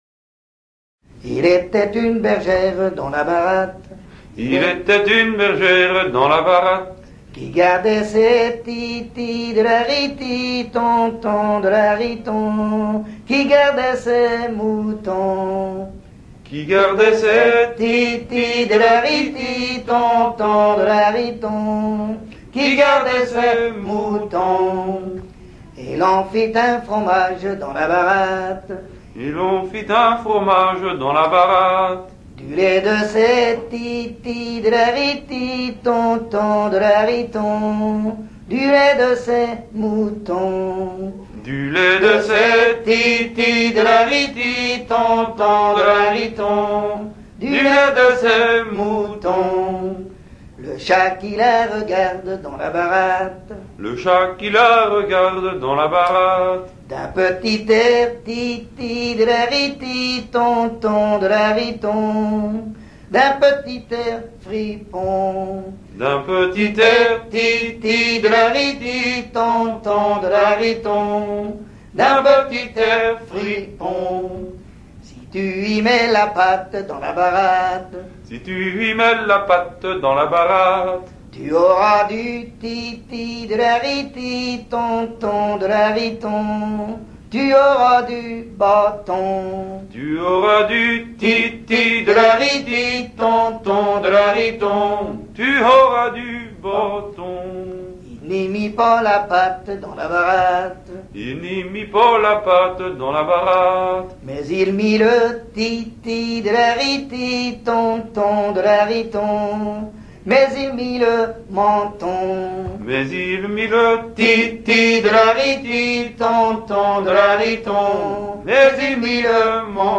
danse : an dro
Pièce musicale inédite